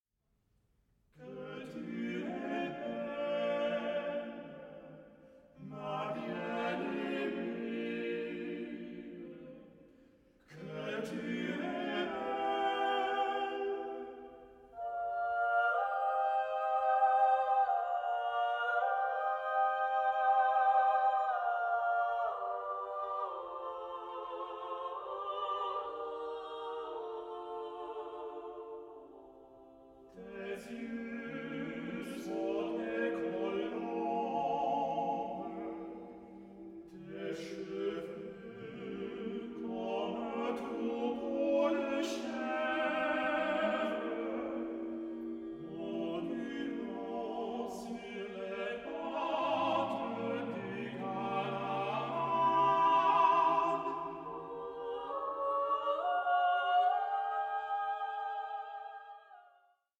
SACRED CHORAL MASTERWORKS